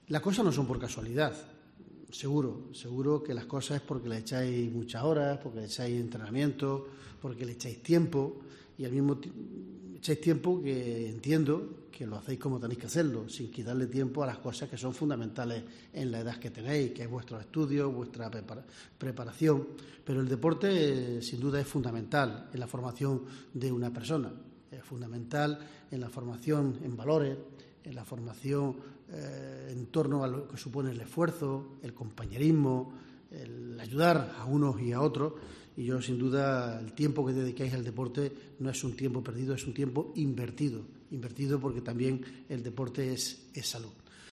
Francisco reyes, presidente de Diputación, dedica unas palabras a la selección infantil de fútbol